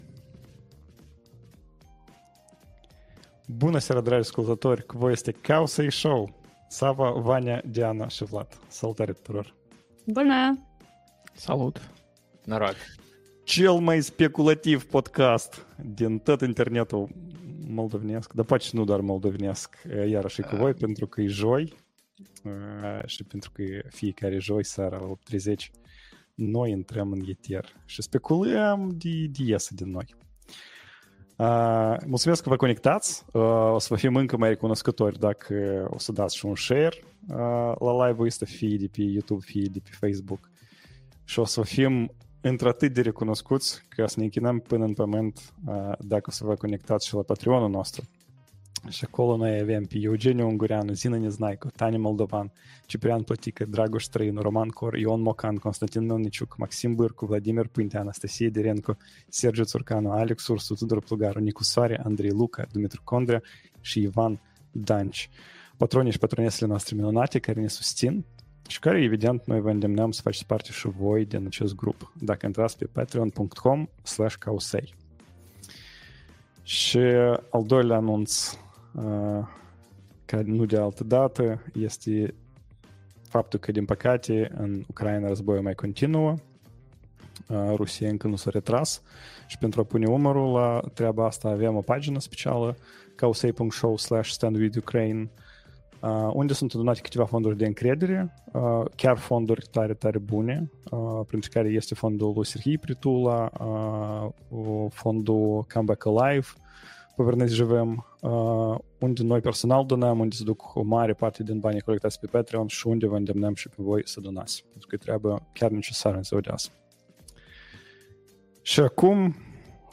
#126: "AI, send money plz", Australia interzice Tiktok, și alte speculații AI December 05th, 2024 Live-ul săptămânal Cowsay Show.